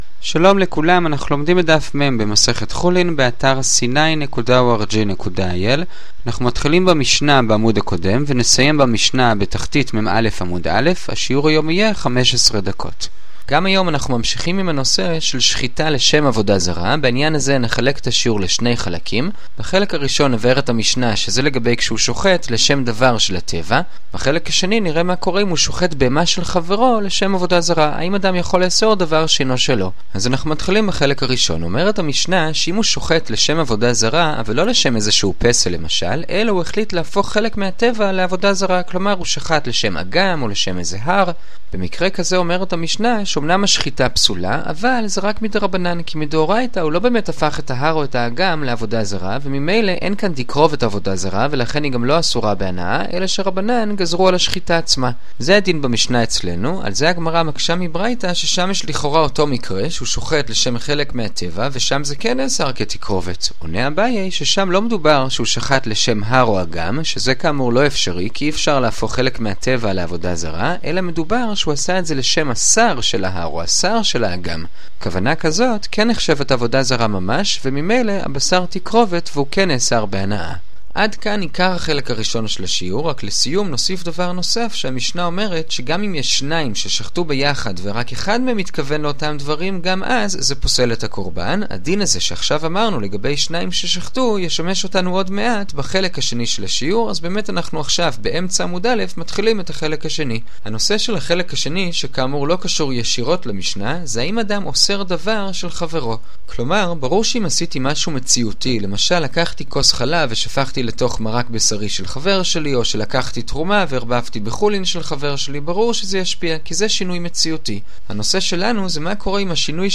חולין דף מ - גמרא דף יומי - הדף היומי ב15 דקות - שיעורי דף יומי קצרים בגמרא